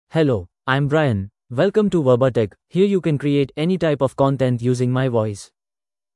Brian — Male English (India) AI Voice | TTS, Voice Cloning & Video | Verbatik AI
MaleEnglish (India)
Brian is a male AI voice for English (India).
Voice sample
Listen to Brian's male English voice.
Brian delivers clear pronunciation with authentic India English intonation, making your content sound professionally produced.